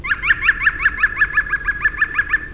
Picchio verde
• (Picus viridis)
Picus.viridis.wav